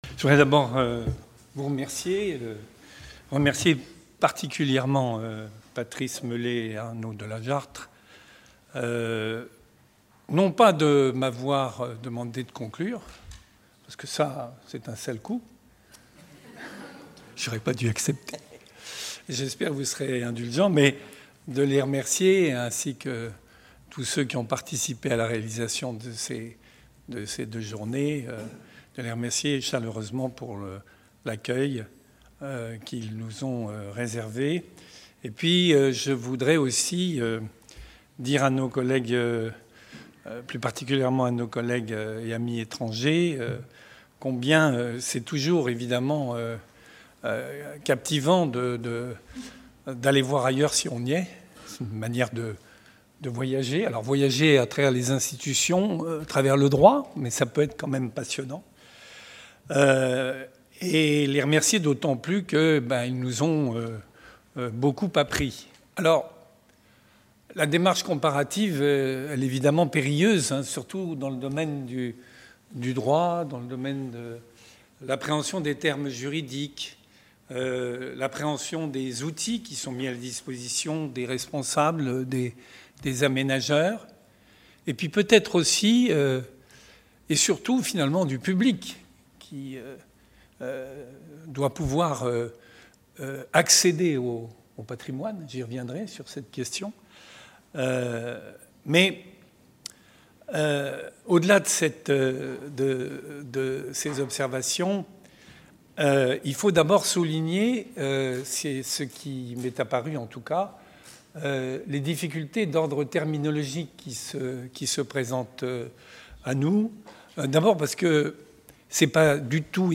10. Synthèse comparative : table-ronde de conclusions (partie 1) | Canal U